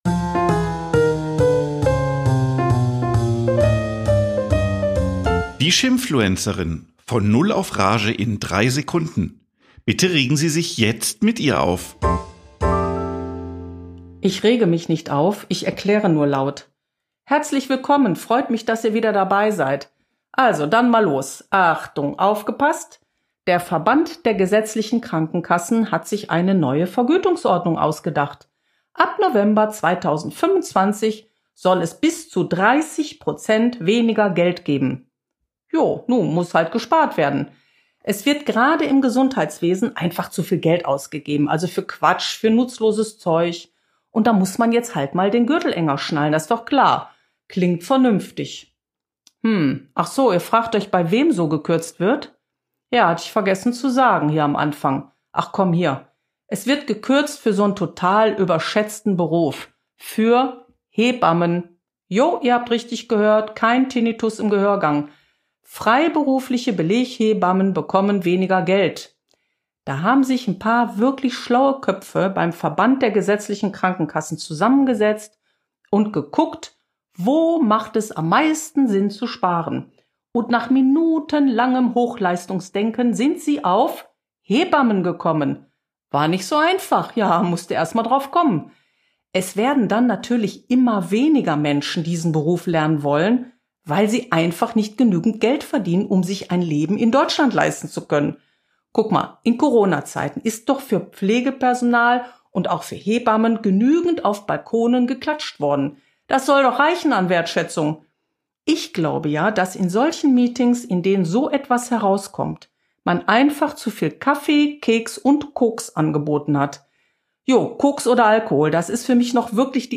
Eine Frau regt sich auf